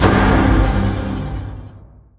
levelup1_2.wav